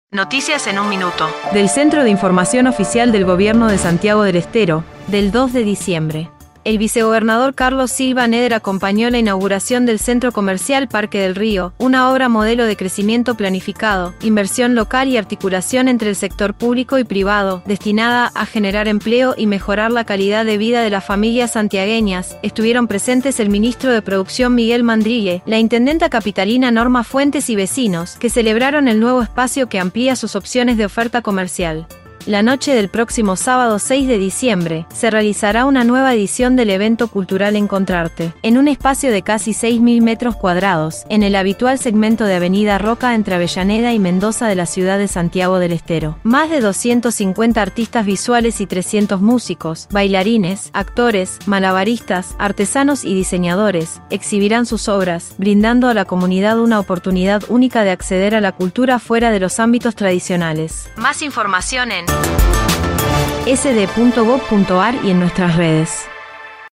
En 1 minuto el reporte de hoy